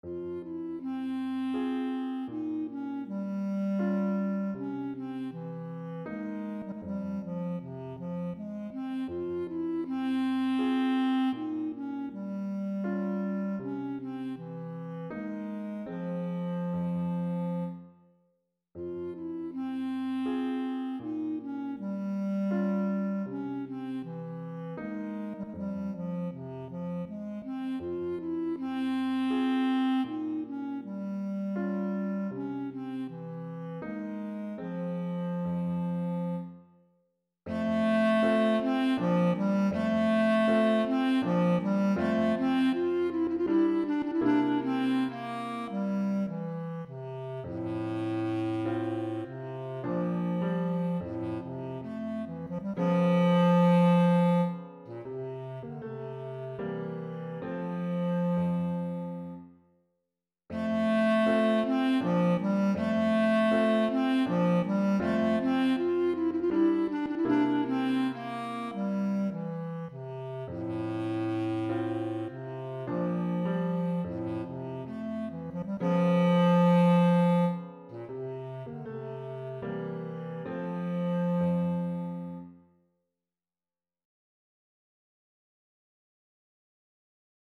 Voicing: Bb Bass Clarinet and Piano